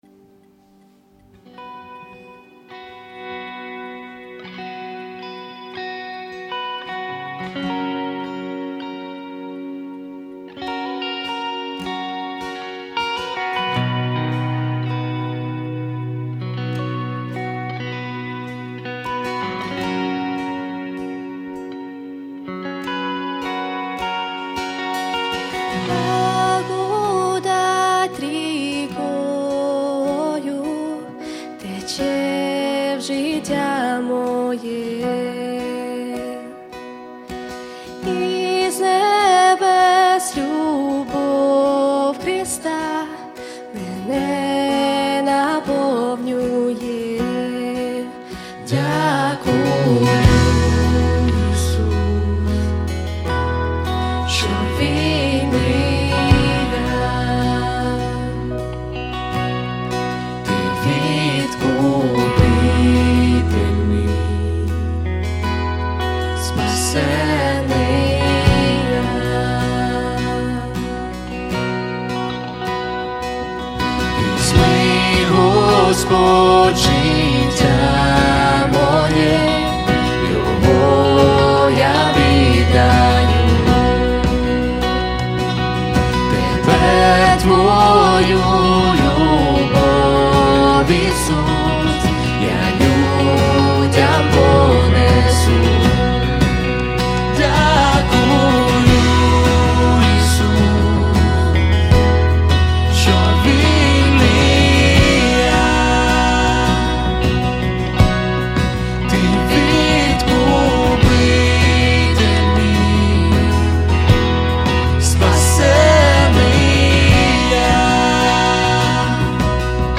1515 просмотров 468 прослушиваний 32 скачивания BPM: 158